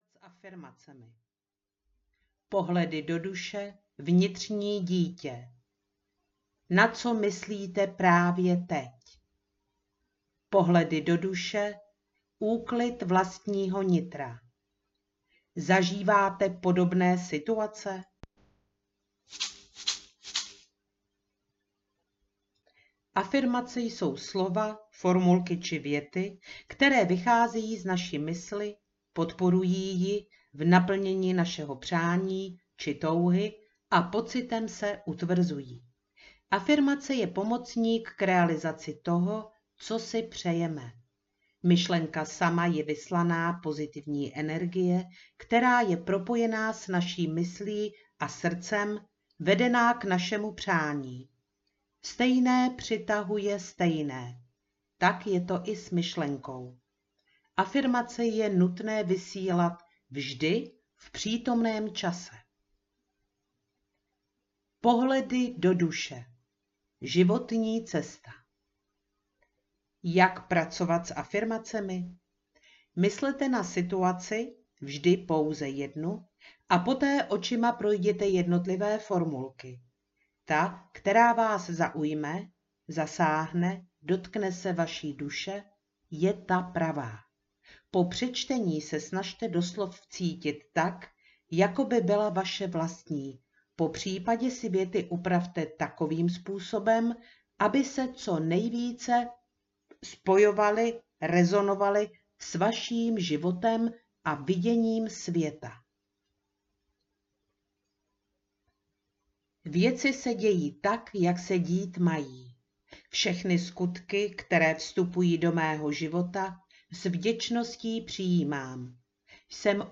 Afirmace - Pohledy do duše audiokniha
Ukázka z knihy